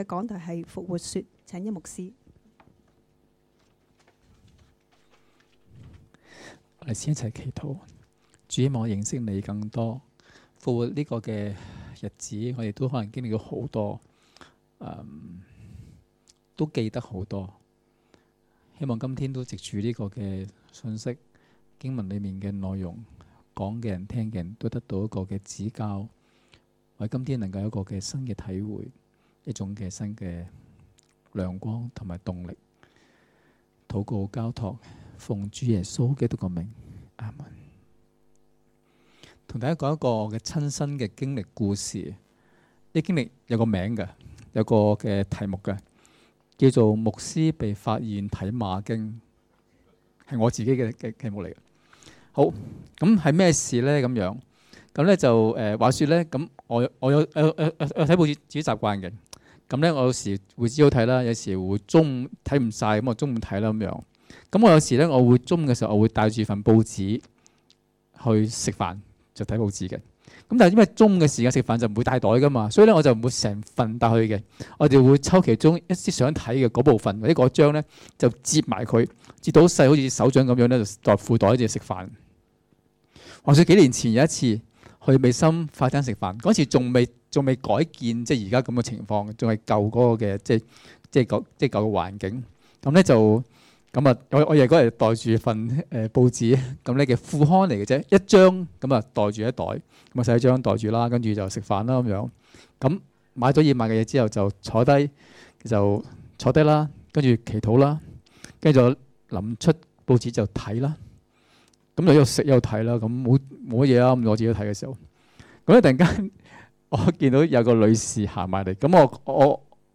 2017年4月15日及16日崇拜講道